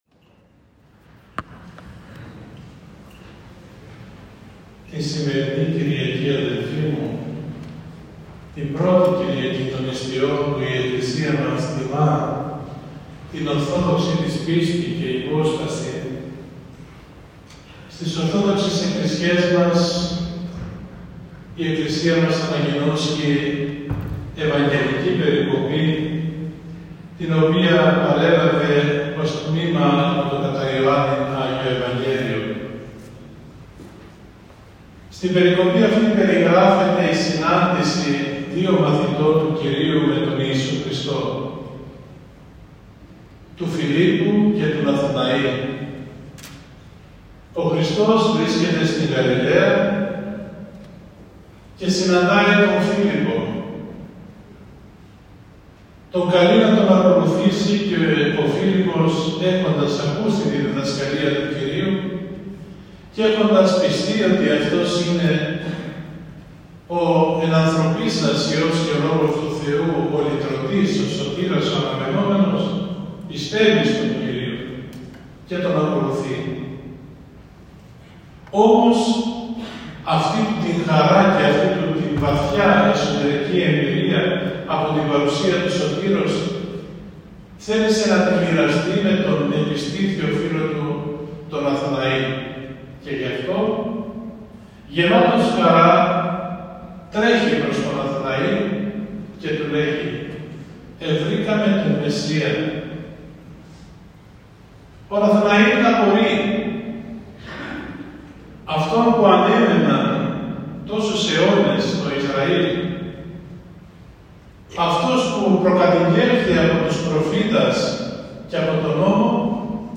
Παρουσία πλήθους κόσμου και τηρουμένου του υγειονομικού πρωτοκόλλου προστασίας εορτάσθηκε στον Ιερό Μητροπολιτικό Ναό Μεταμορφώσεως του Σωτήρος Καλαμαριάς η Α΄ Κυριακή της Αγίας και Μεγάλης Τεσσαρακοστής, η επονομαζόμενη κατά την τάξη της Εκκλησίας και ως Κυριακή της Ορθοδοξίας.
Της Αρχιερατικής Θεία Λειτουργία πρoεξήρχε ο Σεβασμιώτατος Μητροπολίτης Νέας Κρήνης και Καλαμαριάς κ. Ιουστίνος.
Μπορείτε να ακούσετε το κήρυγμα του Σεβασμιωτάτου στο παρακάτω ηχητικό: